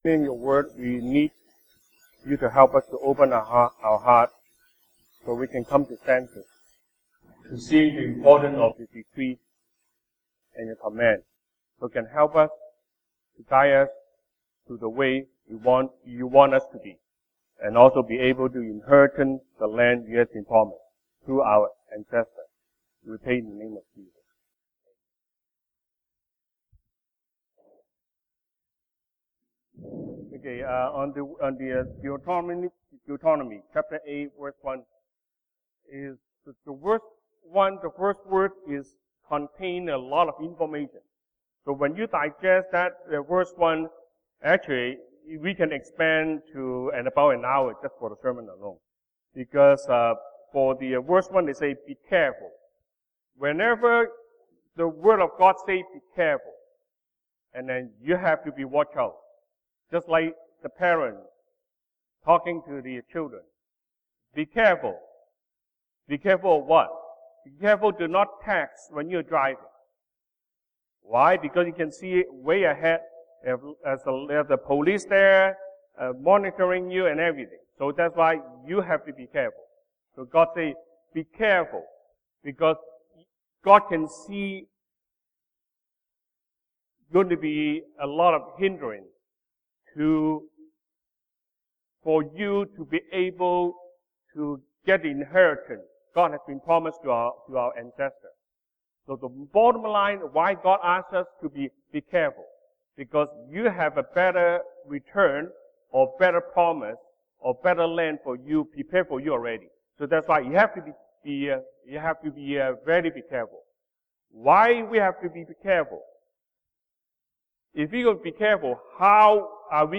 Sunday Service English